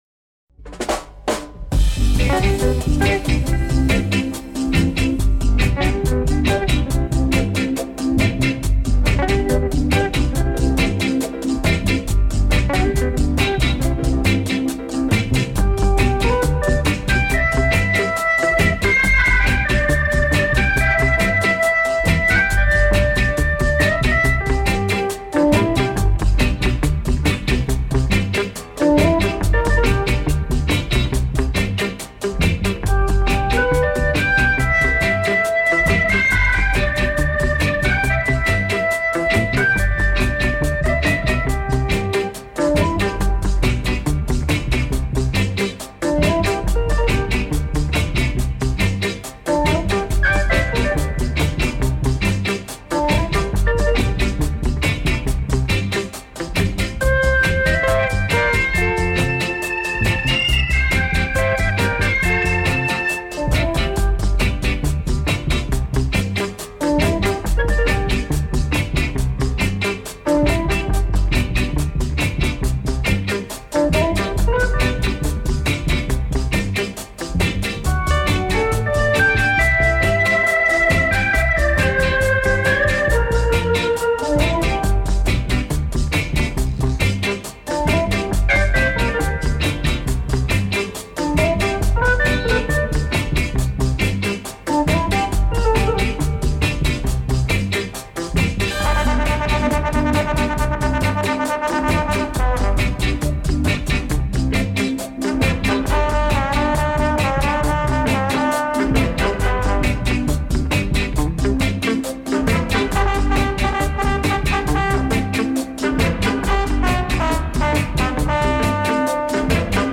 Organ
Saxophone
Vocals